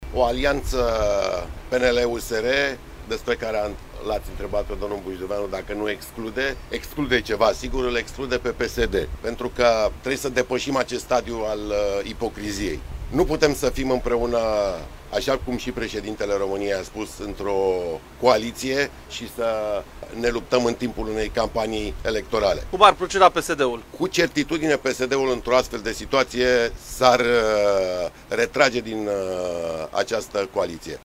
Daniel Băluță, primar Sector 4: „Trebuie să depășim acest stadiu al ipocriziei”